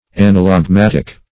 Anallagmatic \An`al*lag*mat"ic\, a. [Gr.